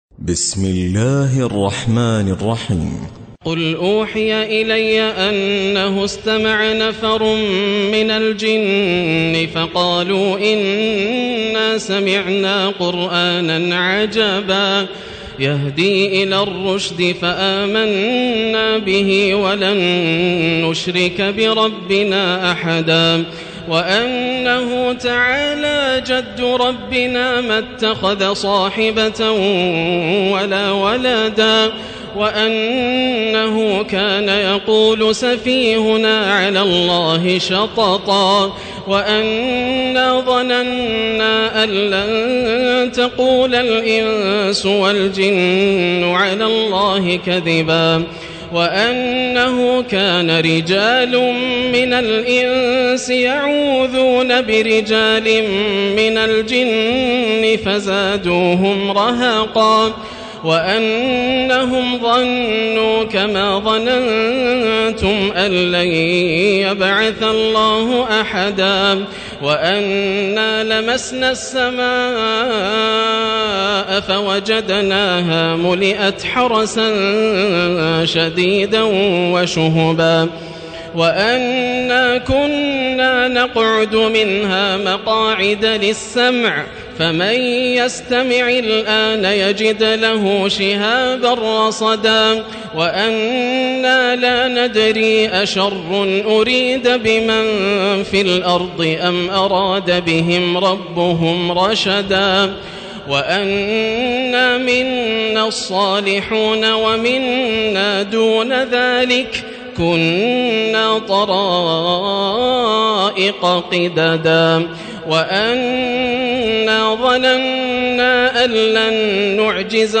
تراويح ليلة 28 رمضان 1437هـ من سورة الجن الى المرسلات Taraweeh 28 st night Ramadan 1437H from Surah Al-Jinn to Al-Mursalaat > تراويح الحرم المكي عام 1437 🕋 > التراويح - تلاوات الحرمين